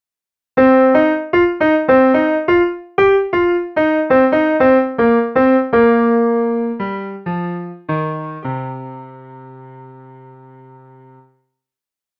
Supposing, you have a recording of a blues guitar solo in MP3 format and you would like to notate them in music sheets.
As a tip, if your piece tempo is slow (such as the guitar solo example previously), you can try 1/8 as the shortest note on import.
And if you like to listen how the edited MIDI (rendered by the music sheet on MuseScore) would sound like (using piano as the MIDI instrument tone):
You will notice that it sounds much like exactly the same with the original audio.